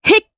Xerah-Vox_Attack2_jp.wav